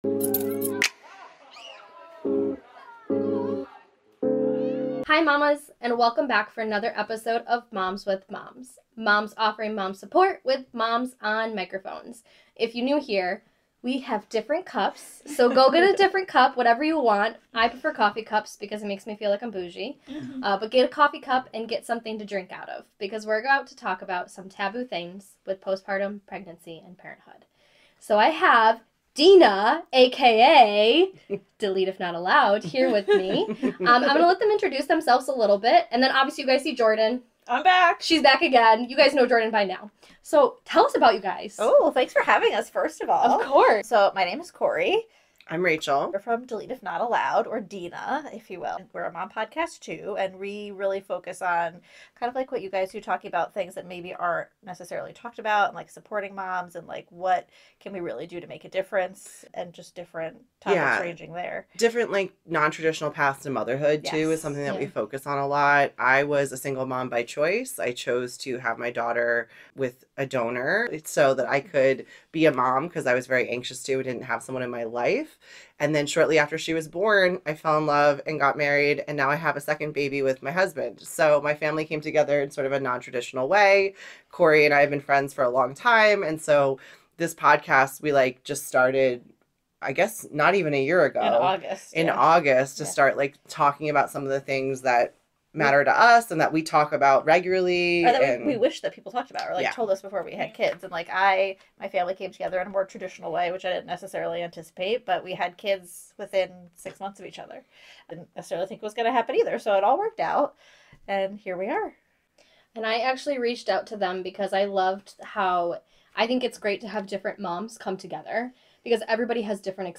Where we have a bestie vent session talking about taboo things with pregnancy and parenthood from MOMS with MOMS.